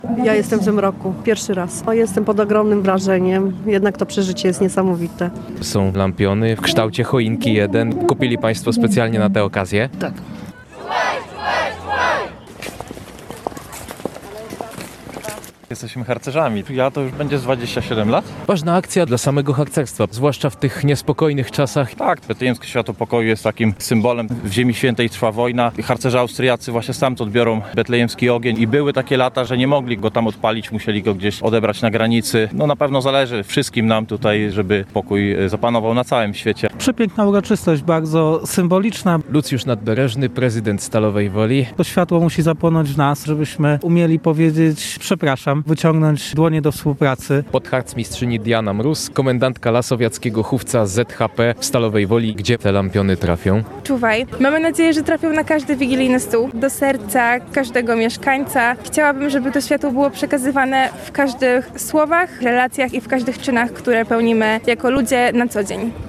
Na rynku w Rozwadowie przekazali je w formie lampionów harcerze z Lasowiackiego Hufca ZHP im. Powstańców Styczniowych.
Tam rozpalono symboliczne ognisko i wygłoszono okolicznościowe przemówienia.